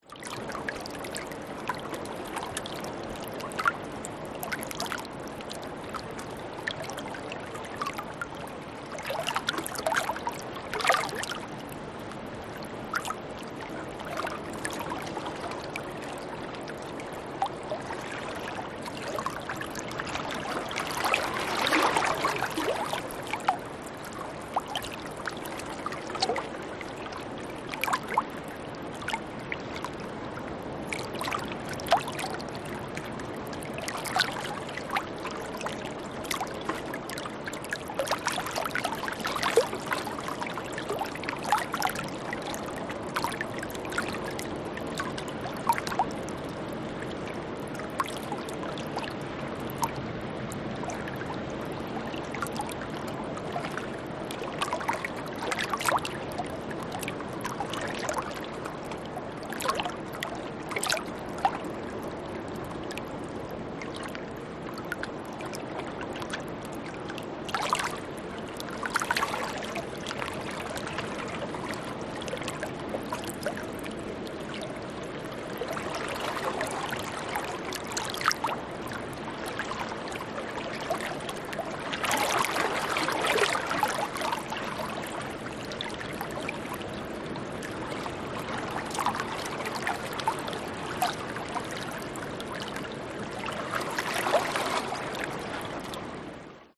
Звуки океана
Шум океанських хвиль, коли відплив далеко